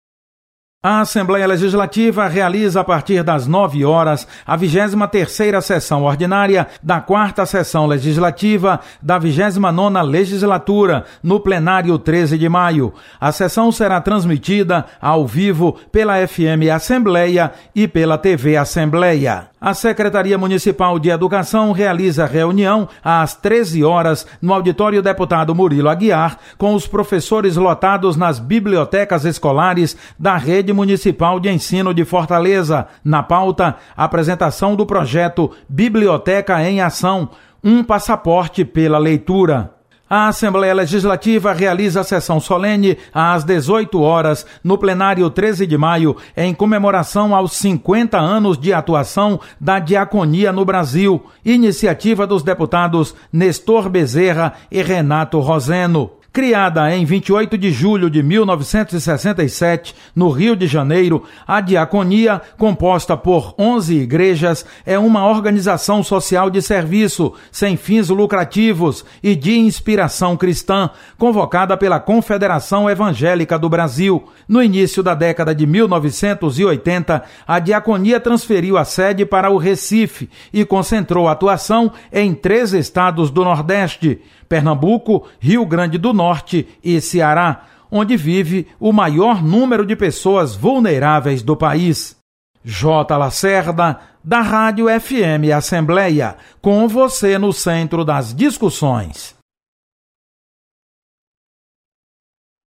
Acompanhe as atividades desta terça-feira (20/03) da Assembleia Legislativa com o repórter